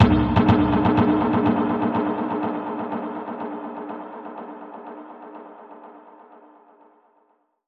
Index of /musicradar/dub-percussion-samples/125bpm
DPFX_PercHit_C_125-02.wav